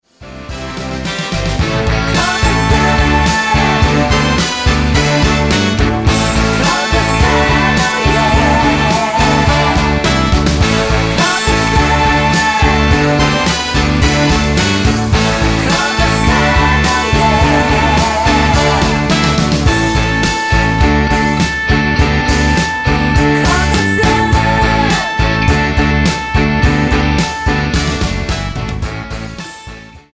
Tonart:E mit Chor